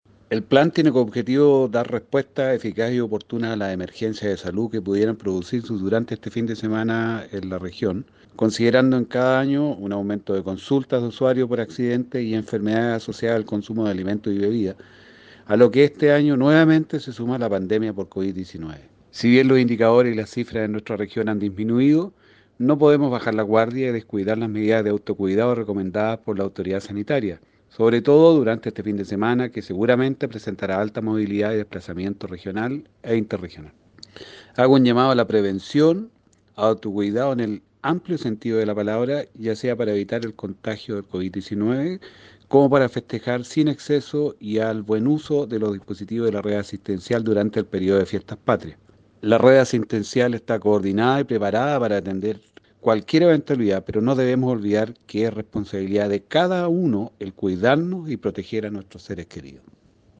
Director-SSV-Victor-Hugo-Jaramillo-activacion-Plan-de-Contingencia.mp3